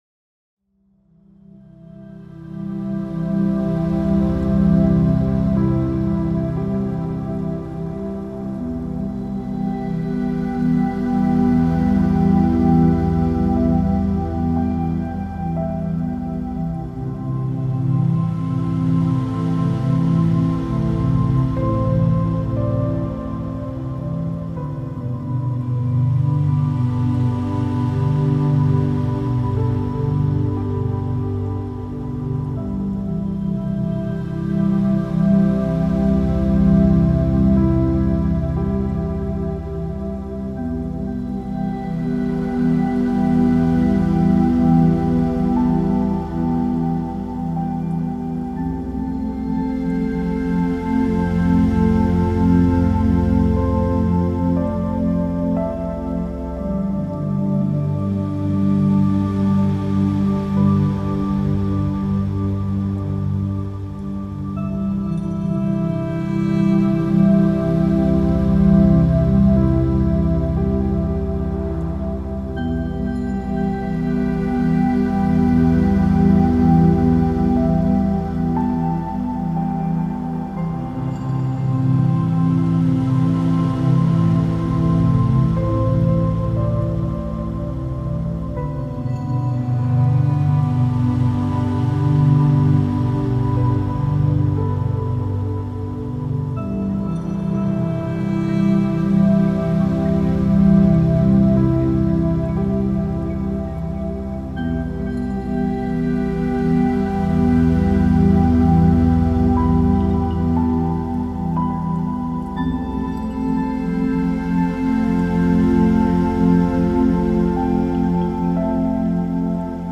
60-Minute Meditation Soundscape – Open Your Chakras, Eliminate Anxiety and Find Inner Calm | Balance Your Energy and Reduce Stress
All advertisements are thoughtfully placed only at the beginning of each episode, ensuring you enjoy the complete ambient sounds journey without any interruptions. This commitment to your uninterrupted experience means no sudden advertising cuts will disturb your meditation, sleep, or relaxation sessions.